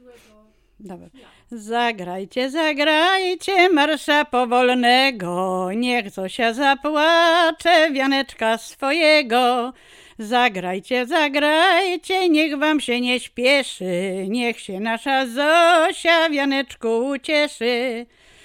Łęczyckie
Weselna
wesele weselne